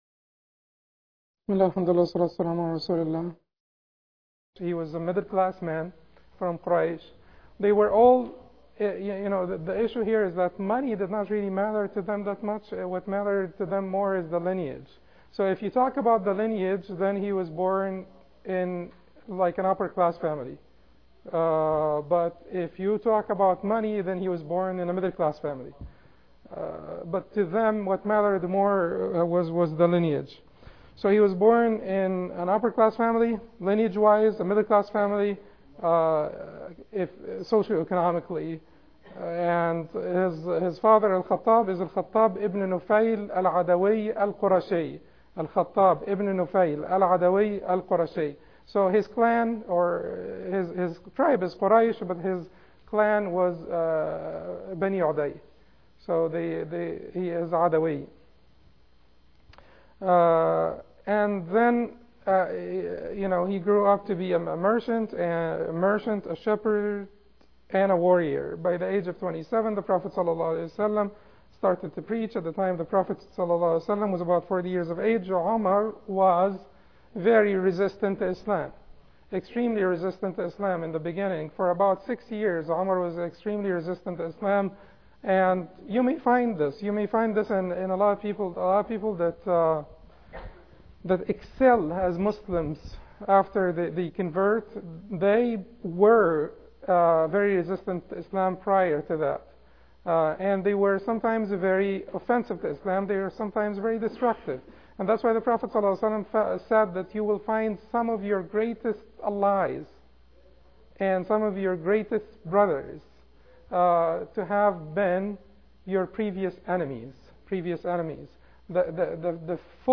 An educational lecture on one of the most significant figures in Islamic History: the Prophet’s (s.a.w) close companion and second khalifa of the Islamic state, Umar ibn Khattab (r.a)